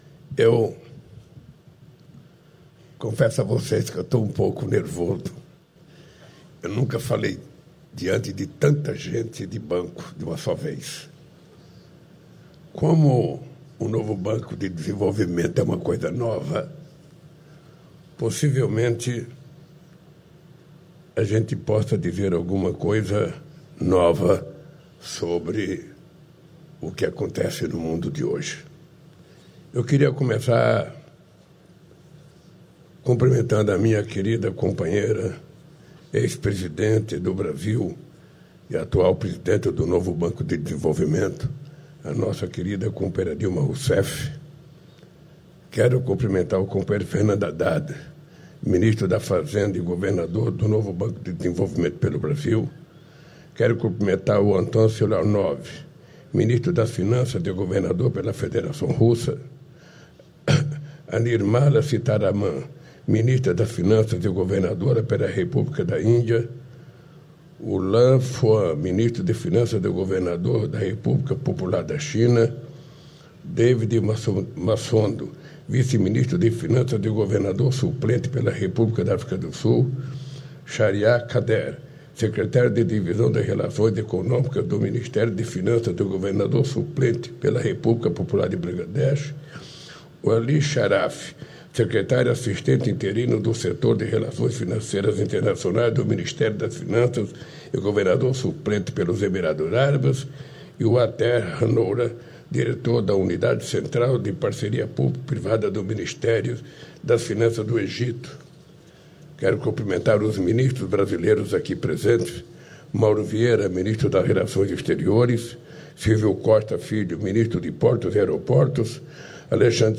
Íntegra do discurso do presidente da República, Luiz Inácio Lula da Silva, em visita e cerimônia de anúncio de investimentos e contratações do Mercado Livre, nesta segunda-feira (7), em Cajamar (SP).